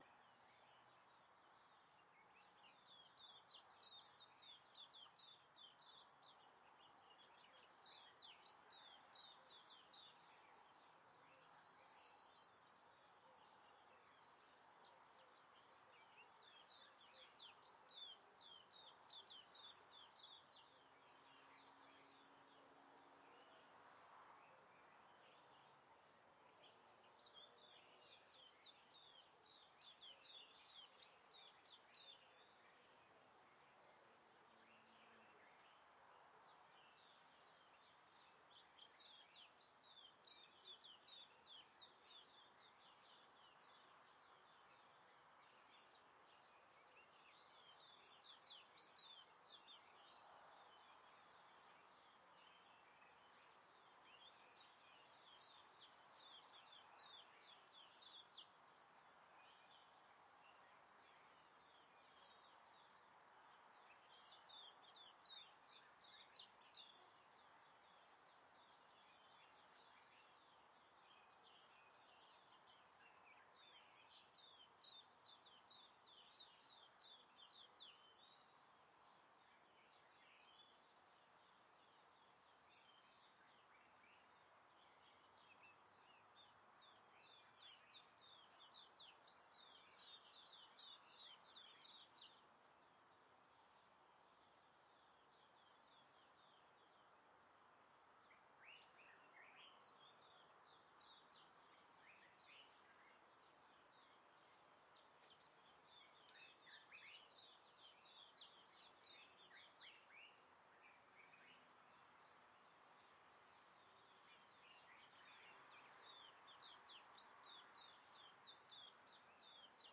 氛围 " 花园氛围
描述：在轻微的凉风习习的日子里，您可以在花园里享受氛围。包括一些鸟的声音。用Zoom H4N录制。
Tag: 场-R ECORDING 鸟鸣声 后院 气氛 微风 性质 花园